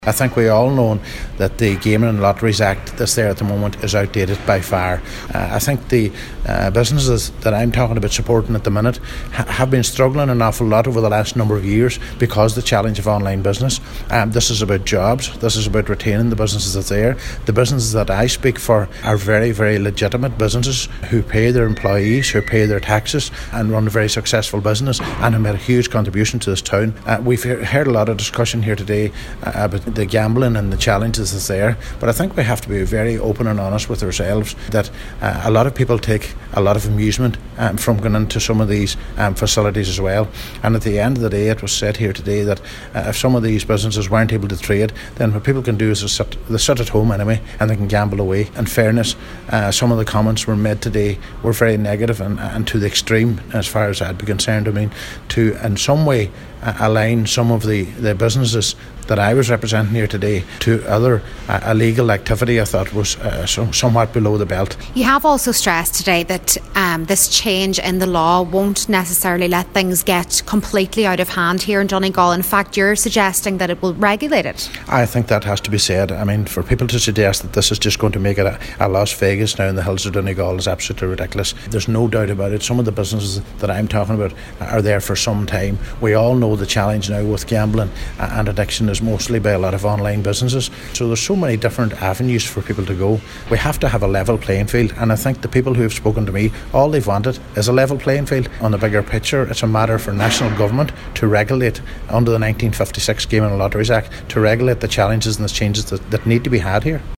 Cllr Brogan, who was in favour of the motion believes the change in law will pave the way for more regulation of gaming machines: